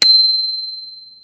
question_markTermékkör Pengetős csengő
Egyszerű, jól szól és könnyen telepíthető.
Szép, erős, éles csengőhang, aki ezt nem hallja meg az megérdemli, hogy elüssék.
cateye_limit_bell.mp3